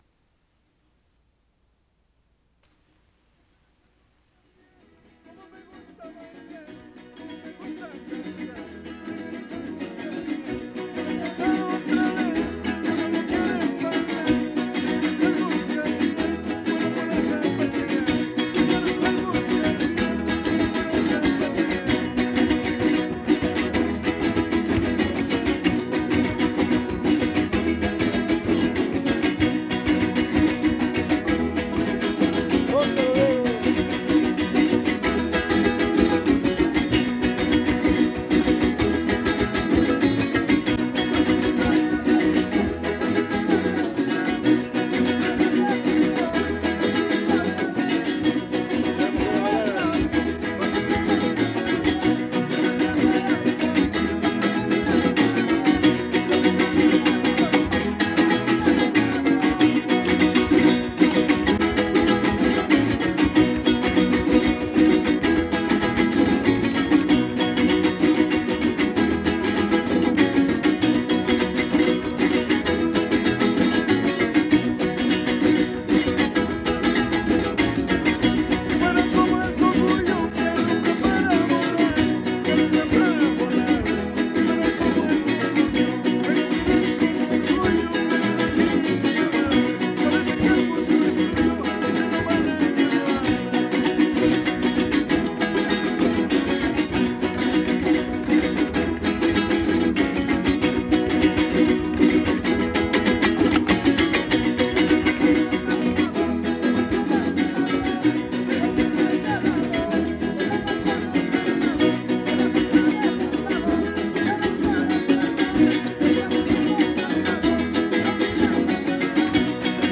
Fandango